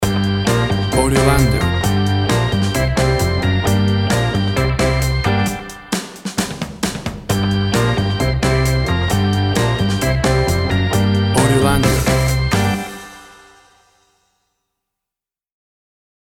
Rock pop of the 60´s vintage.
Tempo (BPM) 135